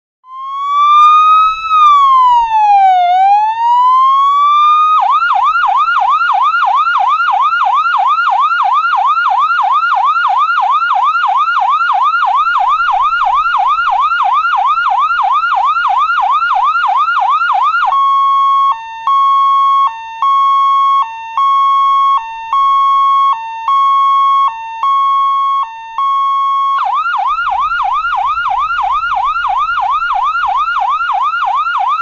Unduh suara Ambulance Wiu Wiu Wiu Mp3
Keterangan: Suara sirine ambulans "Wiu Wiu Wiu..." bisa dijadikan nada dering yang unik dan menarik perhatian.
suara-ambulance-wiu-wiu-wiu-id-www_tiengdong_com.mp3